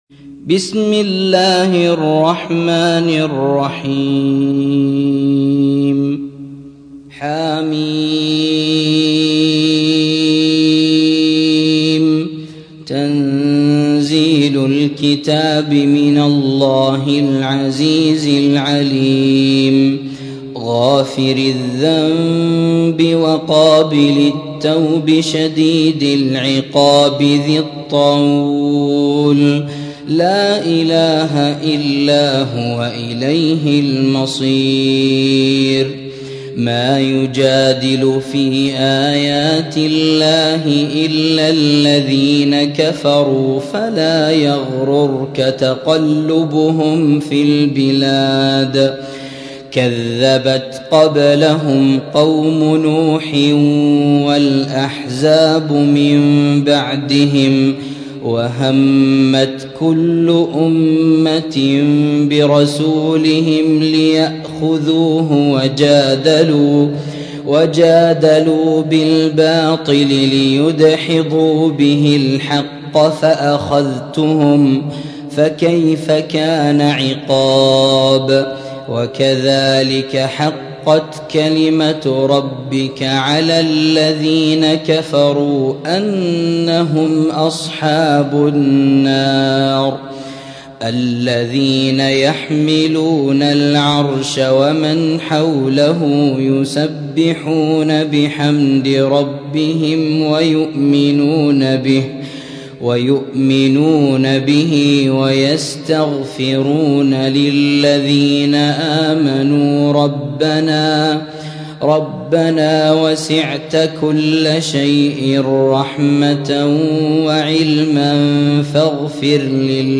موقع يا حسين : القرآن الكريم 40. سورة غافر - سورة مكية ، عدد آياتها : 85 لحفظ الملف في مجلد خاص اضغط بالزر الأيمن هنا ثم اختر (حفظ الهدف باسم - Save Target As) واختر المكان المناسب